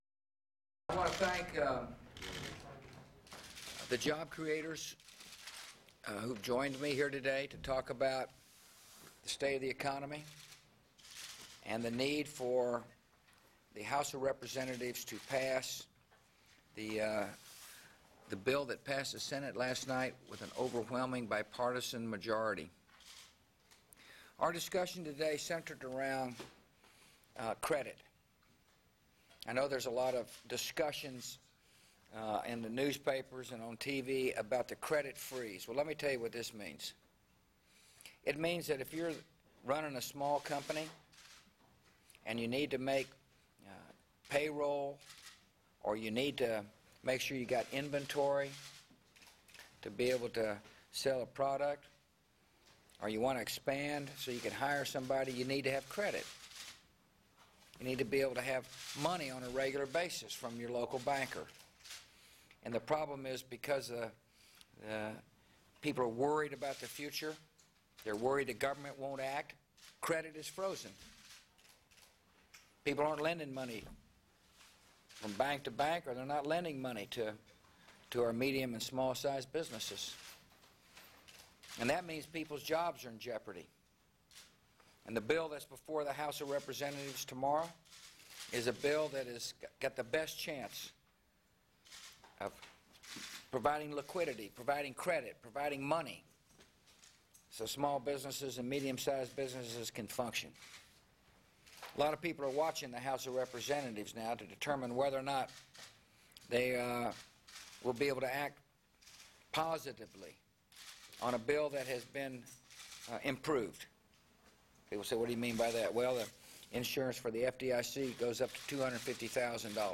U.S. President George W. Bush describes his meeting with representatives of American businesses on the economic rescue package